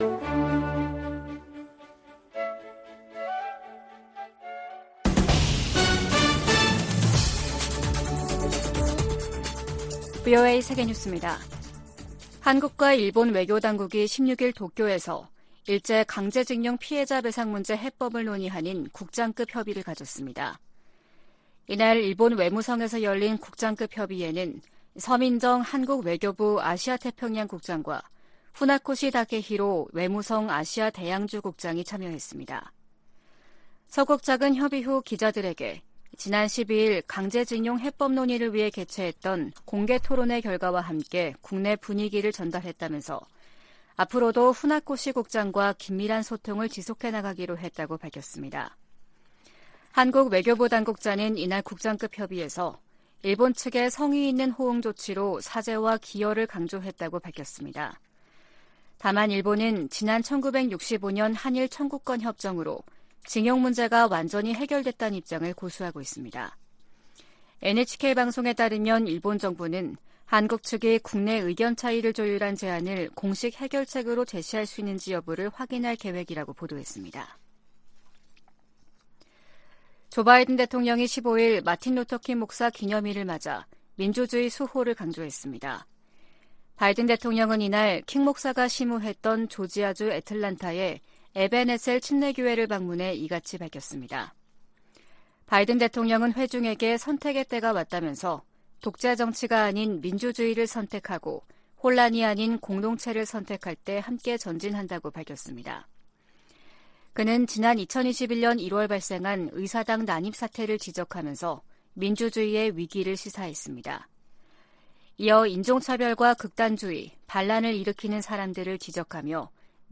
VOA 한국어 아침 뉴스 프로그램 '워싱턴 뉴스 광장' 2023년 1월 17일 방송입니다. 조 바이든 미국 대통령과 기시다 후미오 일본 총리가 워싱턴에서 열린 정상회담에서 한반도 비핵화와 북한의 납치 문제 등을 논의했습니다. 지난해 미국과 한국 정부의 대북 공조가 더욱 강화됐다고 미국 의회조사국이 평가했습니다.